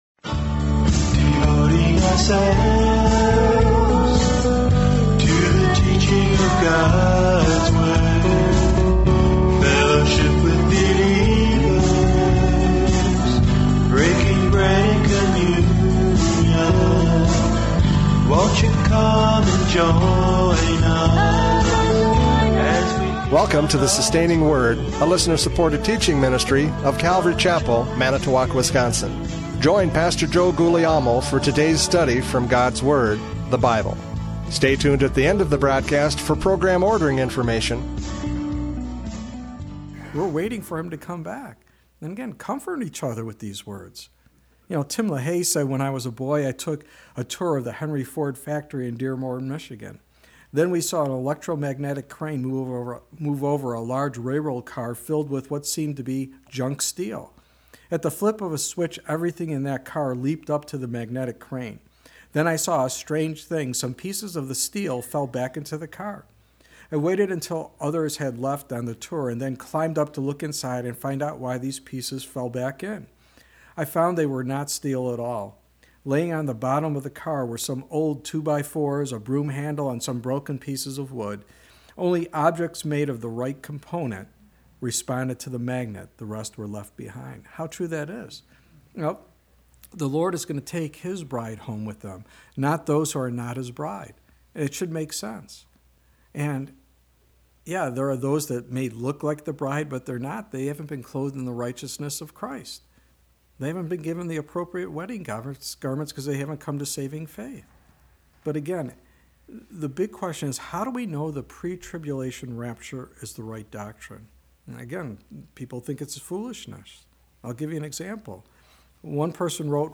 Radio Studies Service Type: Radio Programs « Prophecy Update 2024 Convergence!